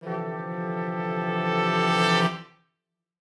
Index of /musicradar/gangster-sting-samples/Chord Hits/Horn Swells
GS_HornSwell-Dmin+9sus4.wav